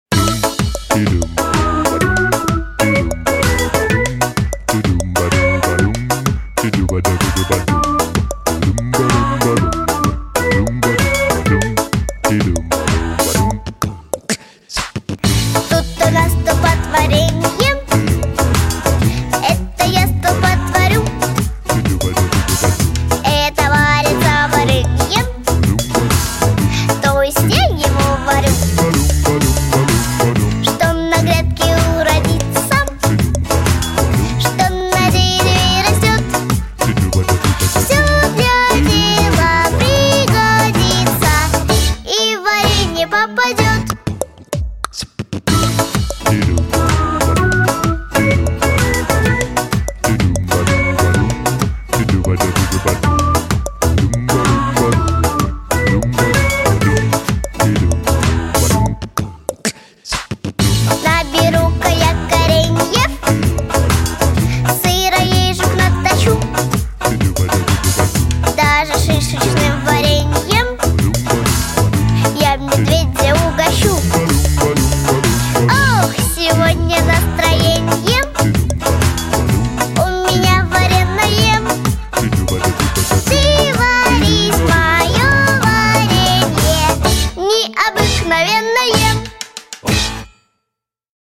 Жанр: "Acapella"